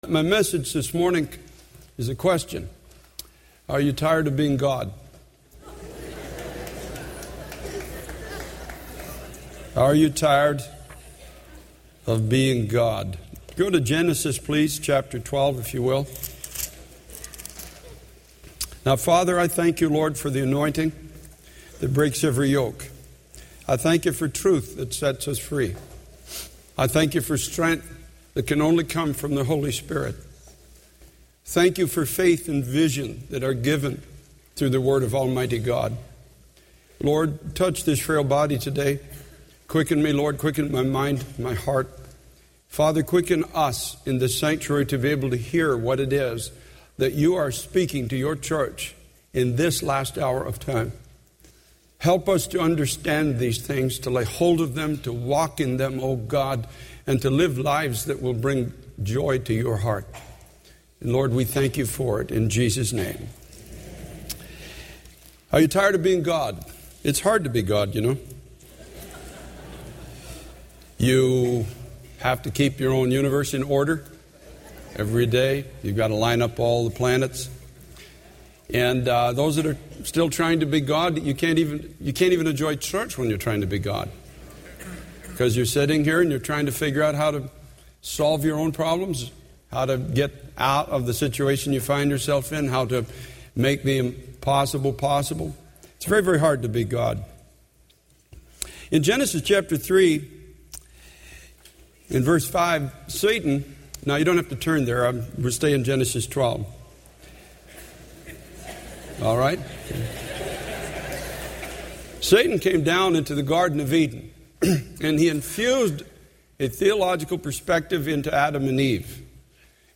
This sermon challenges the listeners to stop trying to be God in their lives and instead surrender to God's promises and grace. It emphasizes the exhaustion and futility of attempting to control one's own destiny and the need to trust in God's supernatural power to transform and bless.